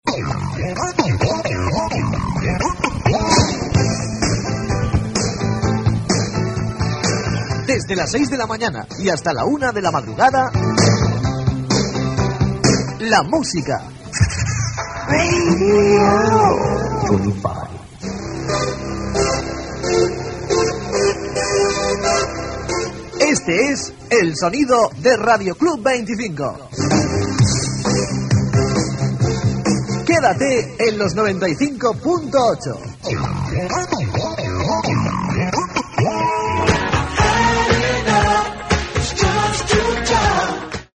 Identificació de l'emissora i horari d'emissió.
FM